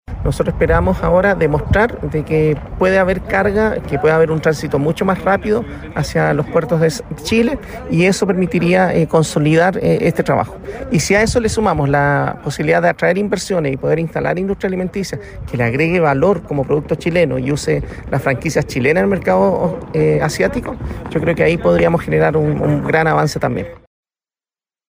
En cuanto al Corredor Bioceánico Vial, el gobernador de Antofagasta, Ricardo Díaz, planteó que es relevante para Chile.